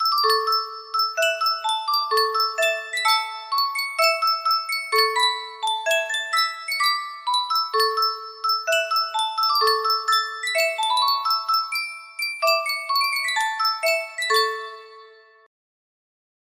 Sankyo Music Box - The Yellow Rose of Texas YE music box melody
Full range 60